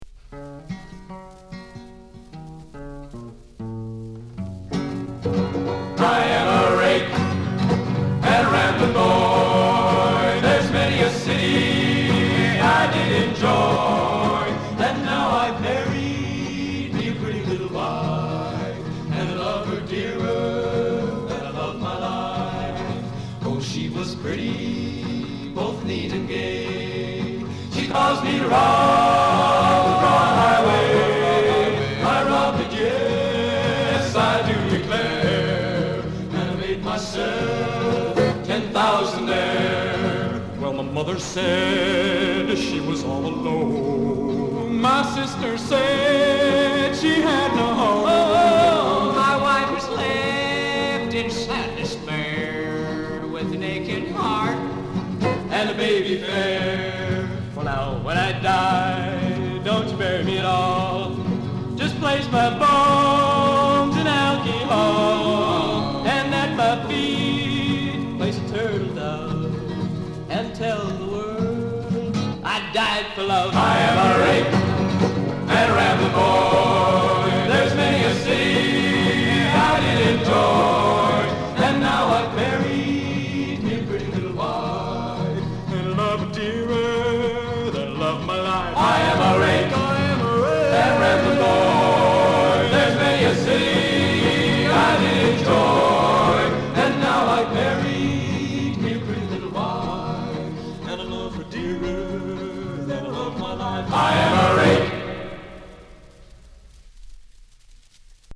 Remember the folk songs we listened to and sang during our years at Whitman?